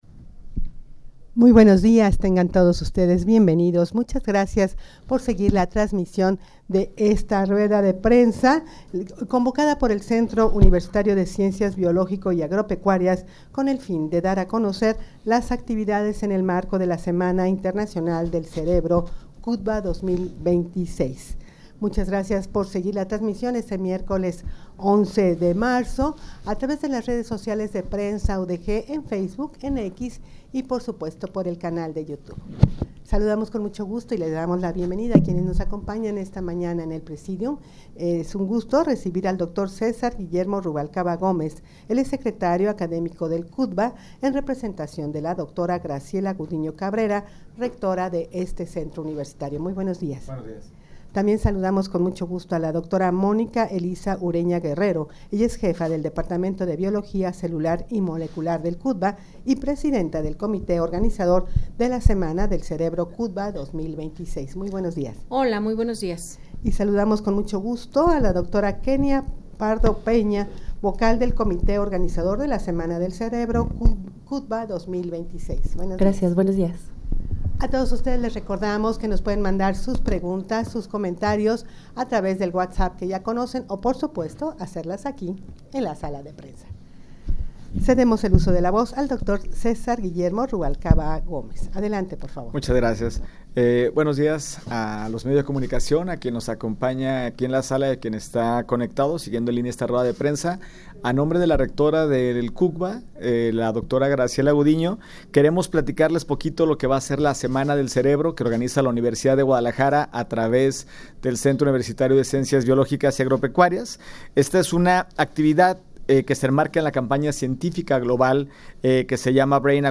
rueda-de-prensa-para-dar-a-conocer-las-actividades-en-el-marco-de-la-semana-internacional-del-cerebro-cucba-2026.mp3